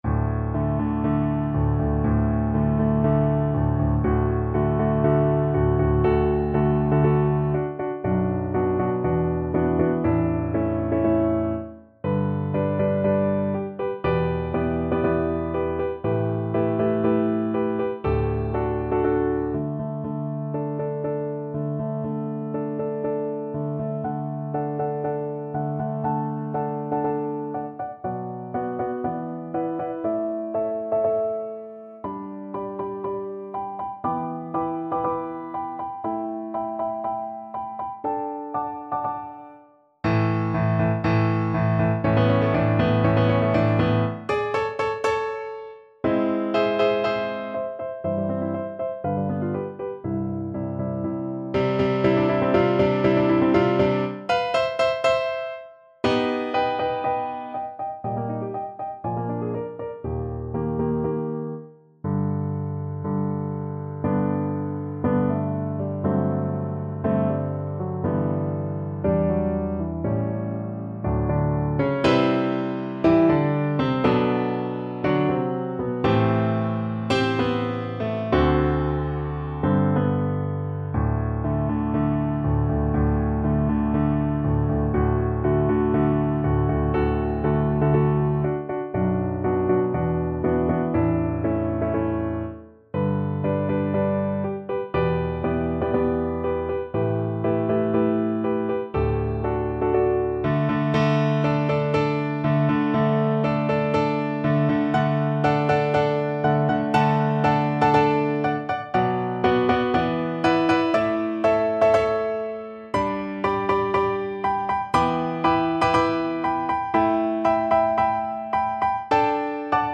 ~ = 120 Tempo di Marcia un poco vivace
Classical (View more Classical Viola Music)